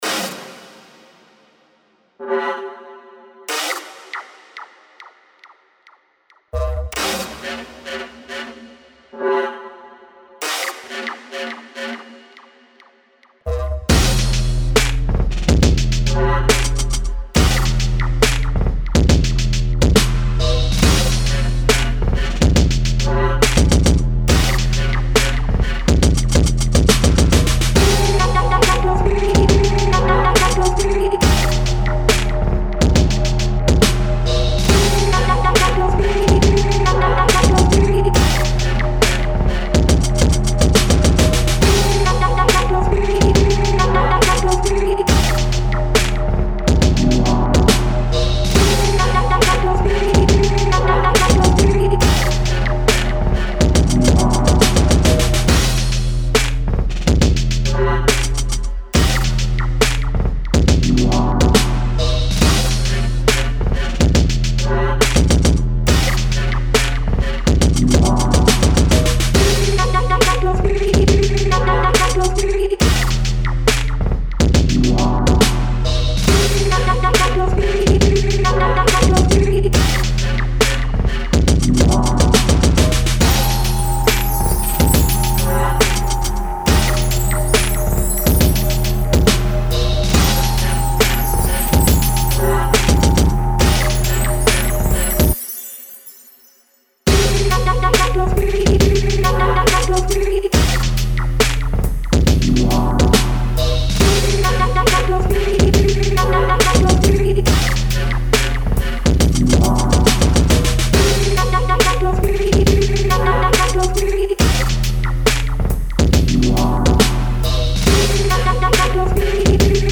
Booty Bass